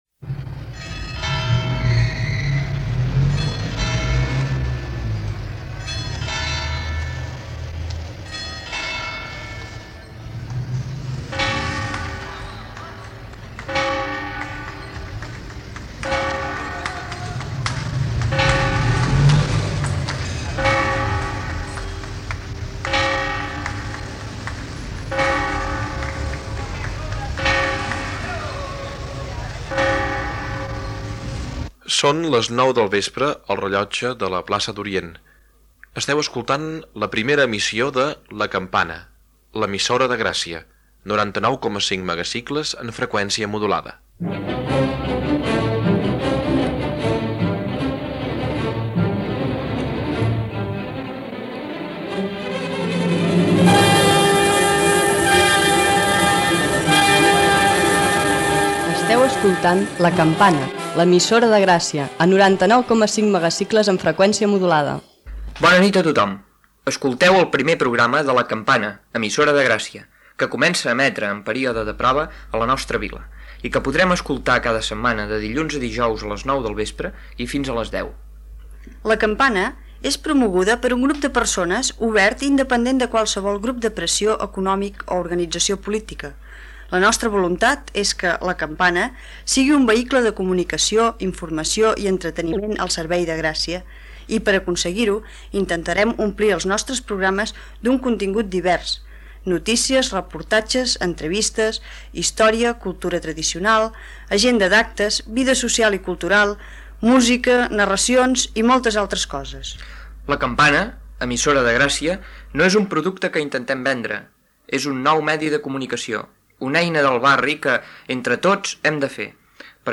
23e1593ec15b6d1b0b1159d9ef4cac2217fd8378.mp3 Títol La Campana Emissora La Campana Titularitat Tercer sector Tercer sector Lliure Descripció Presentació, objectius, indicatiu, contingut de l'emissió, indicatiu, manifest de la Coordinadora de Ràdios Lliures redactat en la trobada del 12 de juny de 1979, notícies del barri. Gènere radiofònic Informatiu Data emissió 1979-06-13 Banda FM Localitat Barcelona Comarca Barcelonès Durada enregistrament 10:26 Idioma Català Notes Primer dia d'emissió de La Campana.